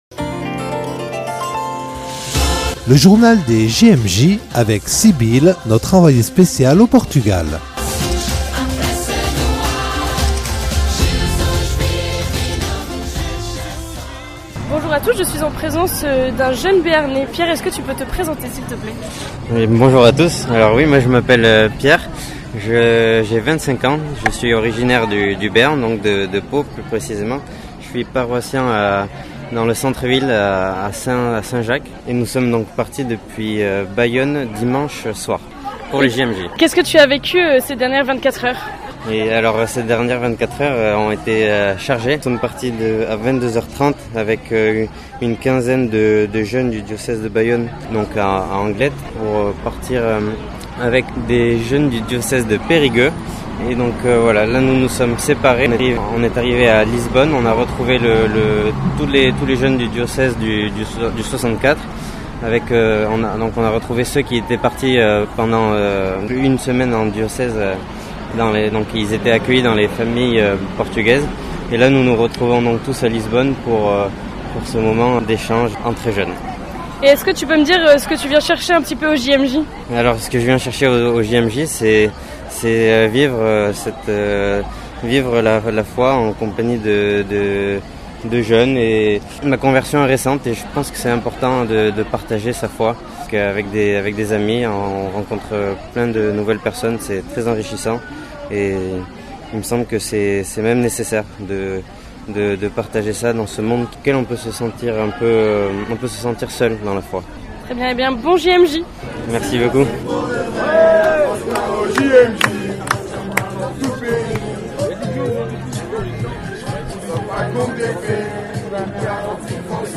Témoignages de jeunes
extraits de la messe d’ouverture des XXXVIIe Journées Mondiales de la Jeunesse.